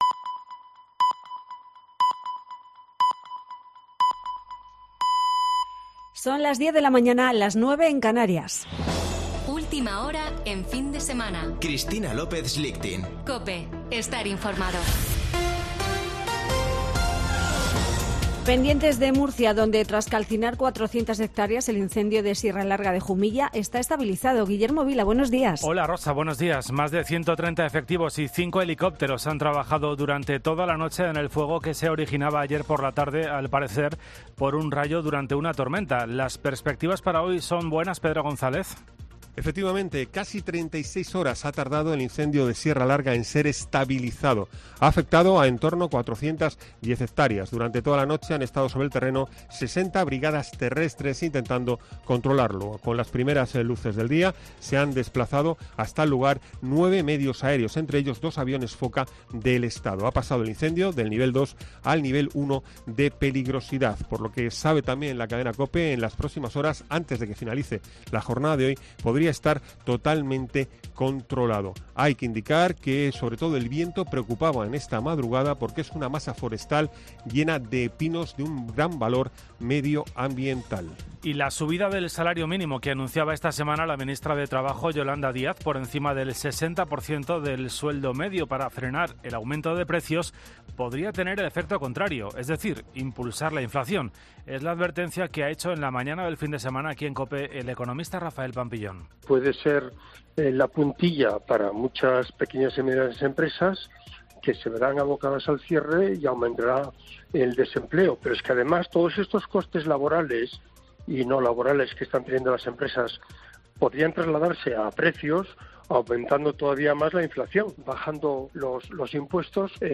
Boletín de noticias de COPE del 28 de agosto de 2022 a las 10.00 horas